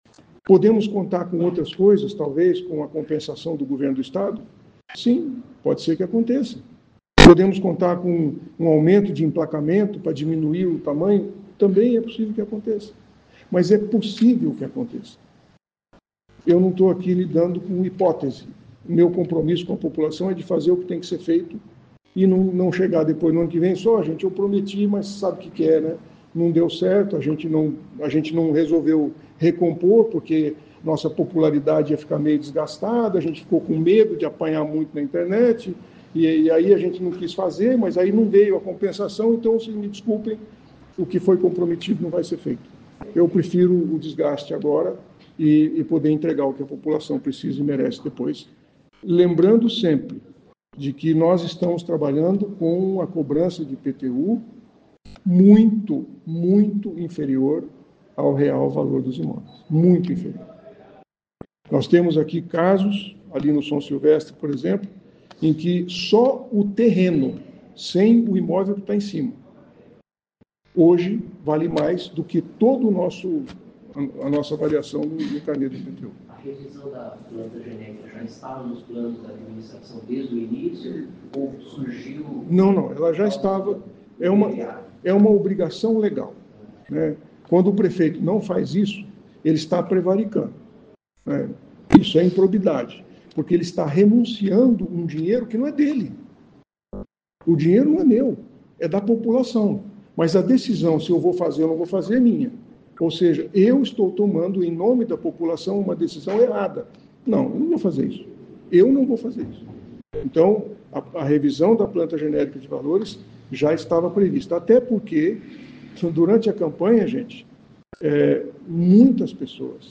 Prefeito Sílvio Barros reúne a imprensa para tirar dúvidas sobre IPTU